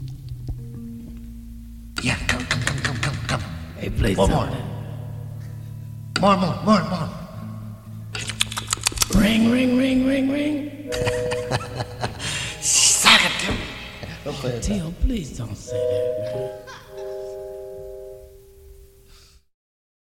the "ring-ring-ring-ring" part